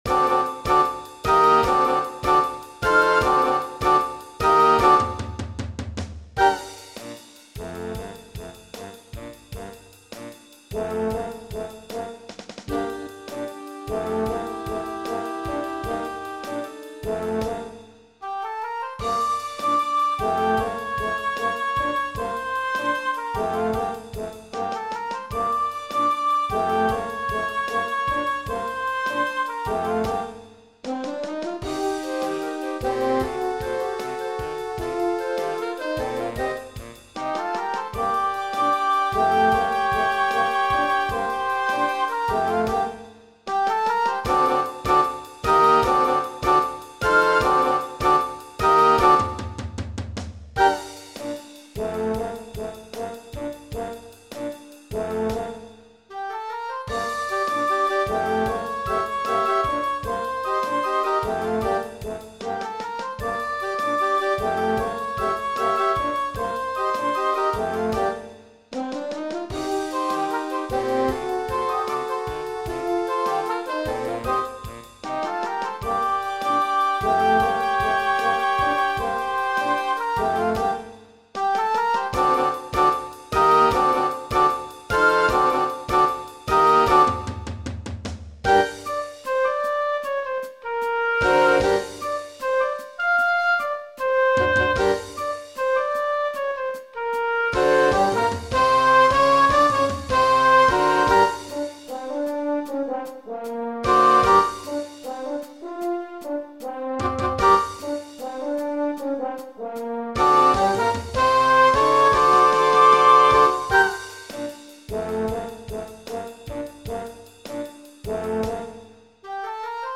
Voicing: Woodwind Quintet and Drumset